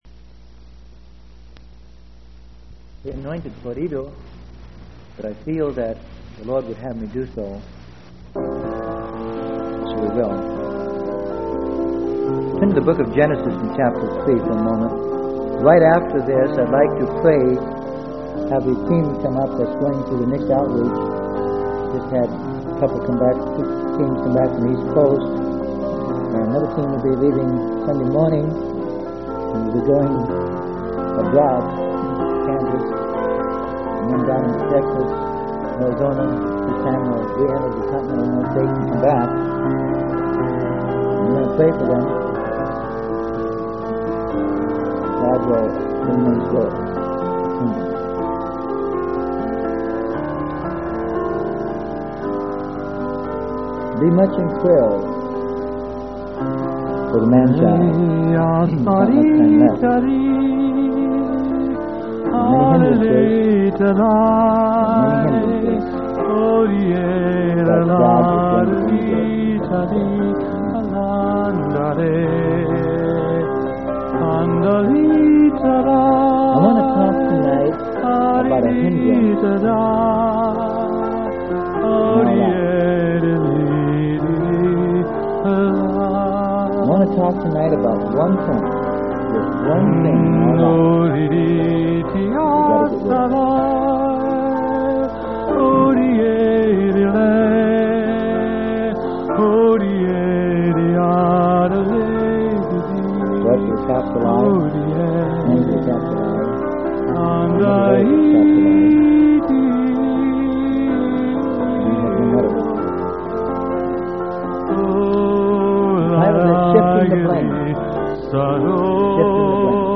Sermon: Shifting The Blame.